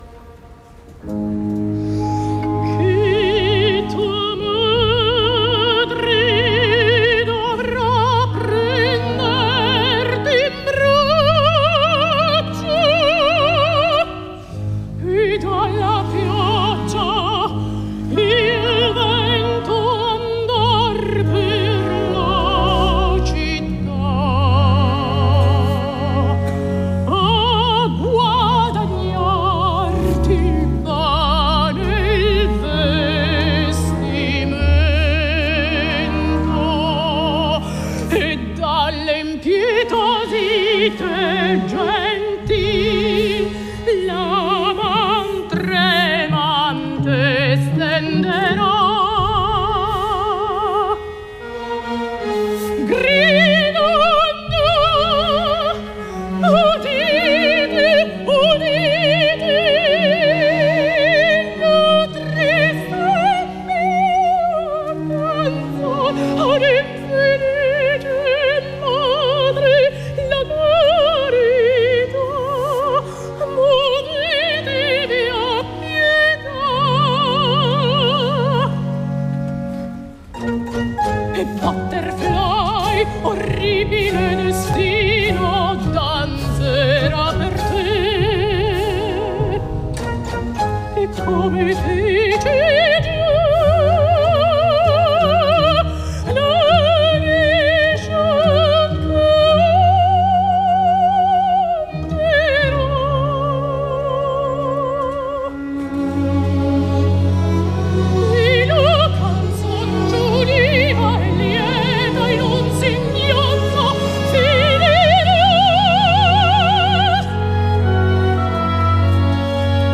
Un cop o els que faci falta, escoltada, m’heu de dir qui és la soprano que ho canta.